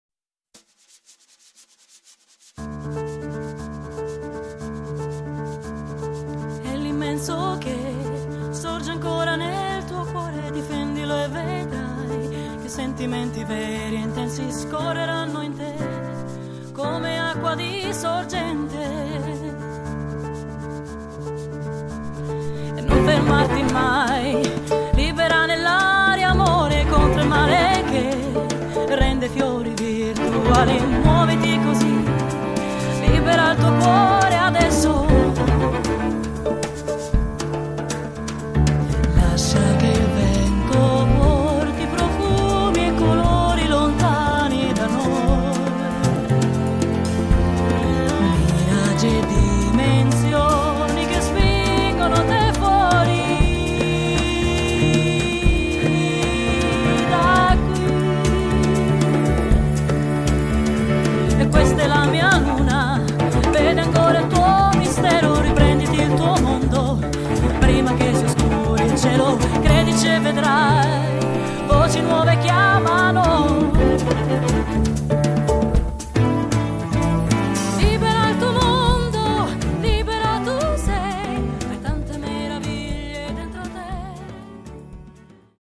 quasi un samba jazz